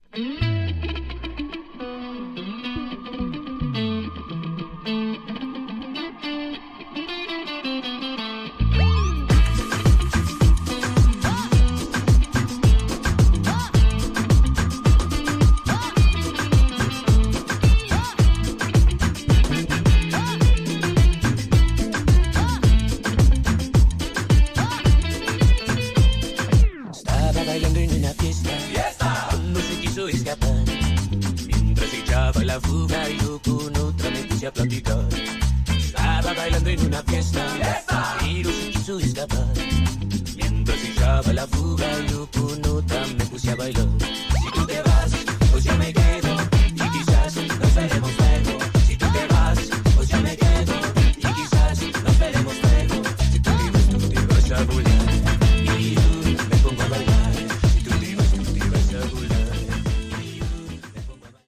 Tags: Cumbia
Super bailables: cumbia con raps, rock, housito.